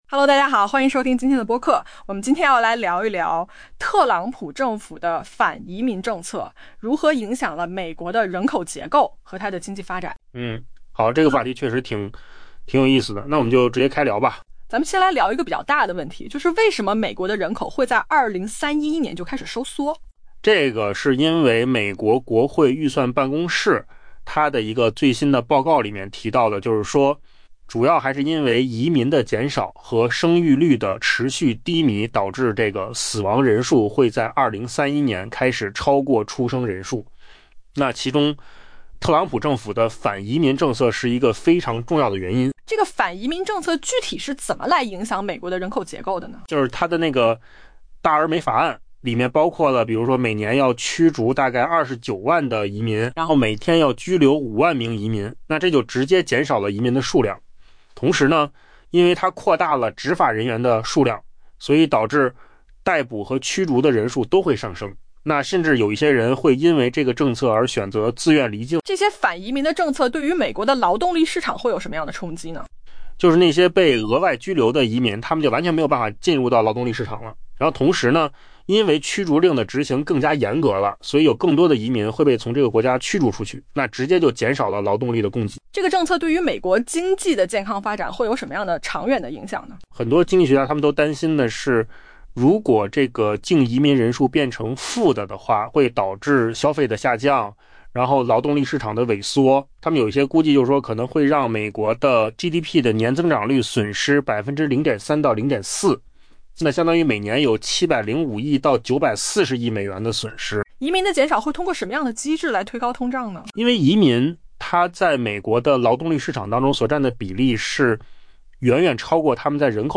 音频由扣子空间生成 下载mp3